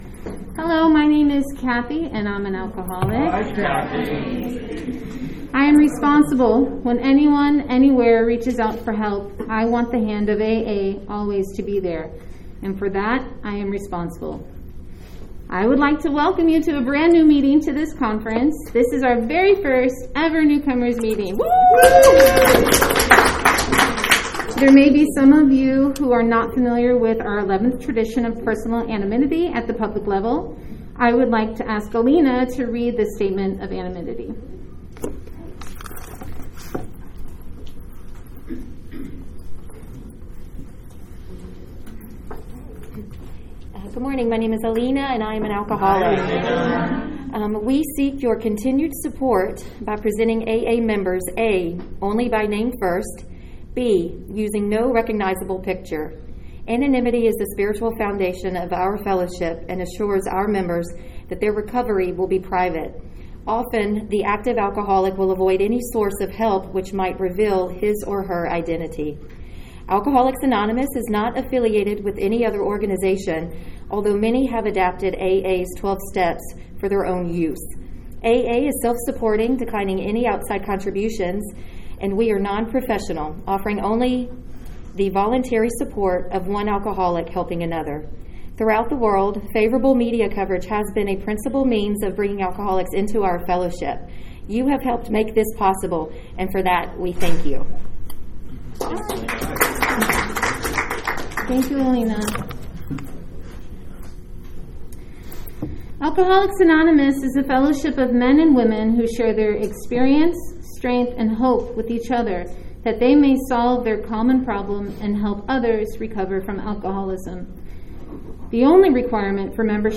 San Fernando Valley AA Convention 2024 - Let Go and Let God